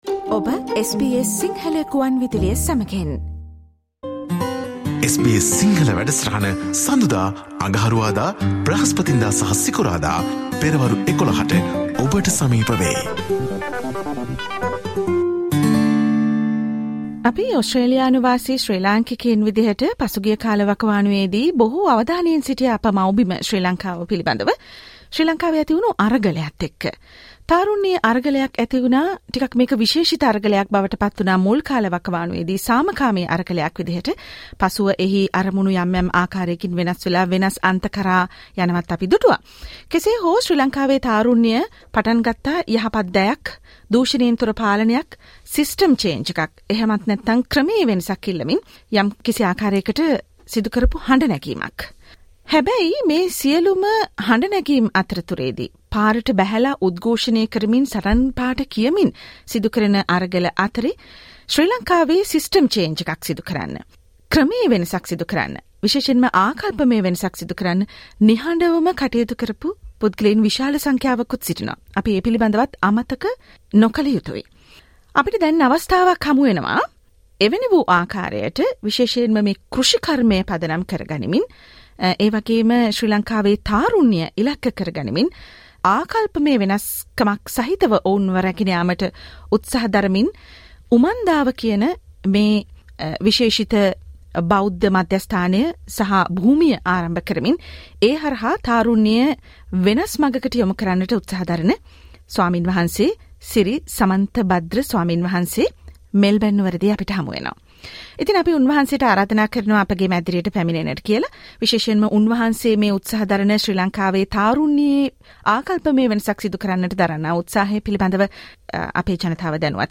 This is a discussion
visited SBS Sinhala radio studios in Melbourne